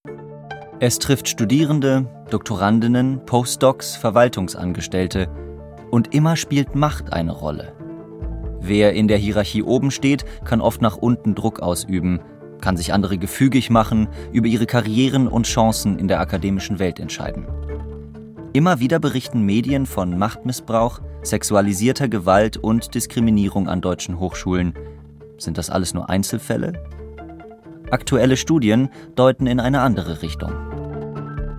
SWR Feature "Machtmissbrauch"